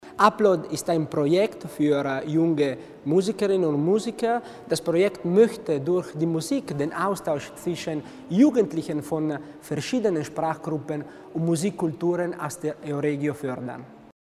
Landesrat Tommasini zur Bedeutung des Projekts Upload